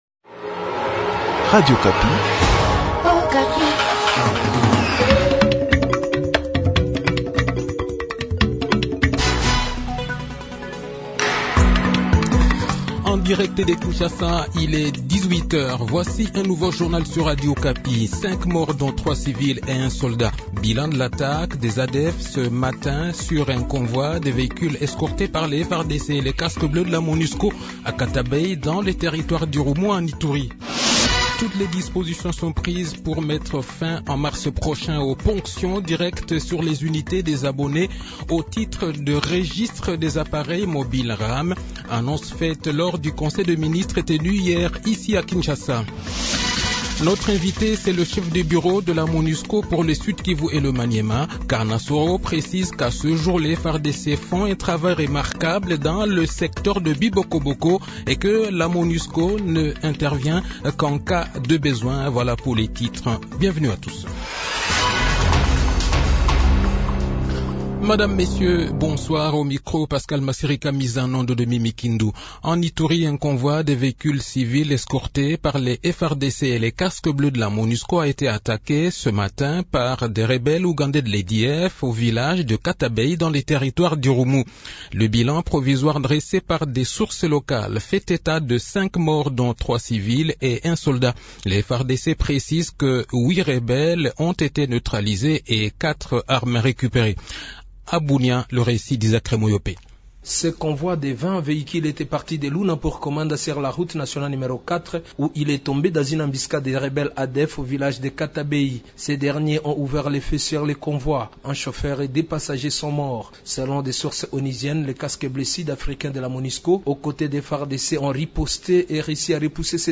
Le journal de 18 h, 19 février 2022